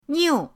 niu4.mp3